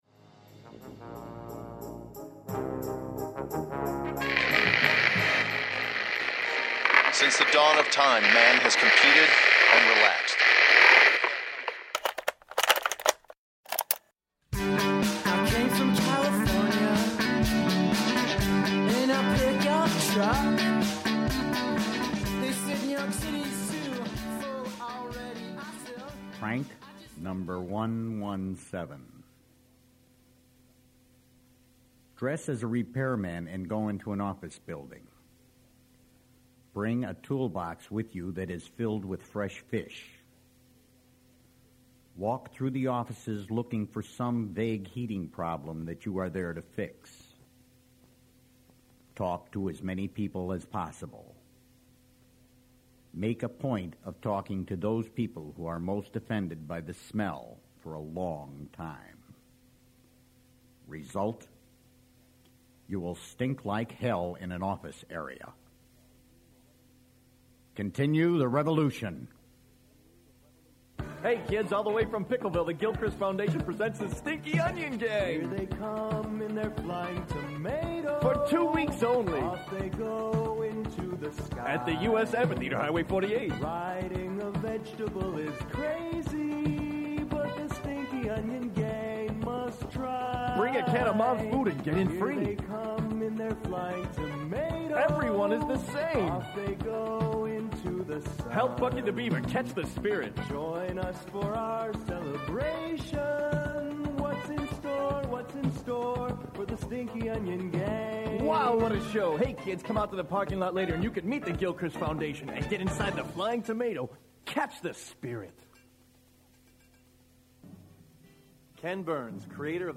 Plucked deep from within the UCB Sports & Leisure vaults, The Basement Tapes is an audio retrospective cataloguing the history of hosts Matt Walsh & Scot Armstrong’s comedic foundations & their evolution over the past 3 decades. This special is a collection of previously lost materials, unseen stage performances, & favorite memories over the years featuring appearances from John Gemberling, Colton Dunn, Jon Stewart, and more.